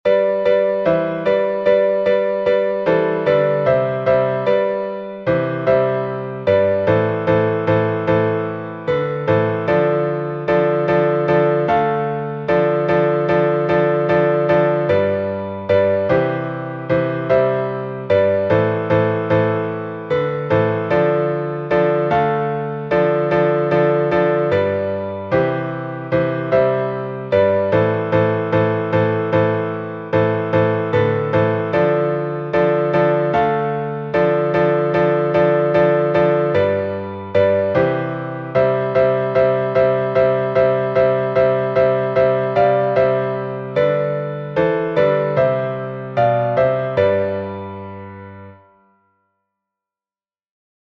Напев Зосимовой пустыни, глас 2